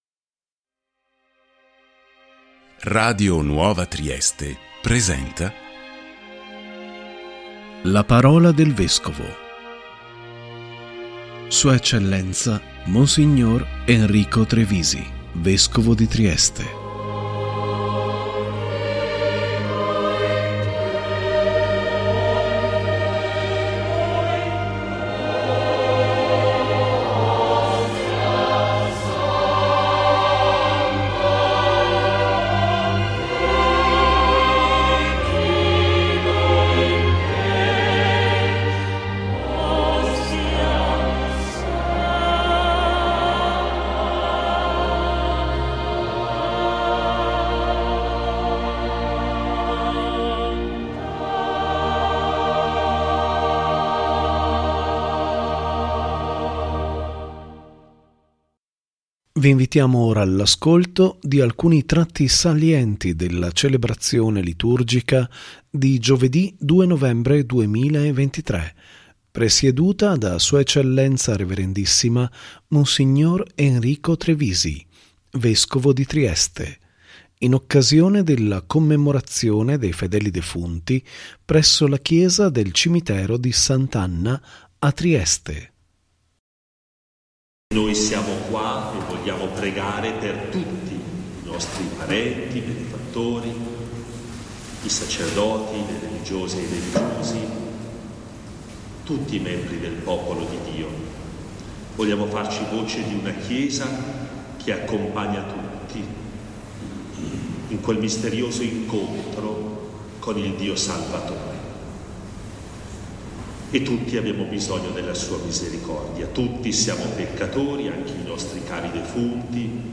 ♦ si è tenuta, Giovedì 2 novembre 2023, la liturgia eucaristica presieduta da Sua Eccellenza Rev.issima Mons. Enrico Trevisi, Vescovo di Trieste, in occasione della Commemorazione dei fedeli defunti presso la Chiesa del Cimitero di S.Anna a Trieste.